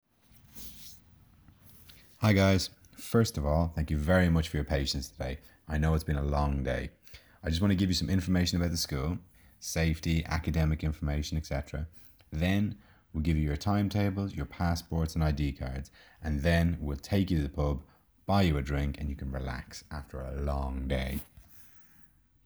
What I used for this lesson was a snippet from the welcome talk the students receive on the first day.